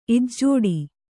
♪ ijjōḍi